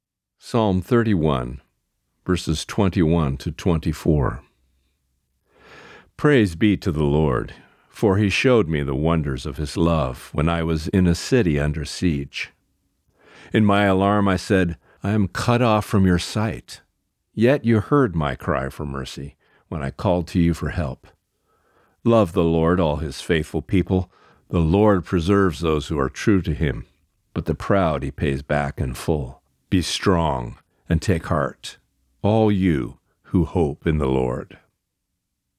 Reading: Psalm 31:21-24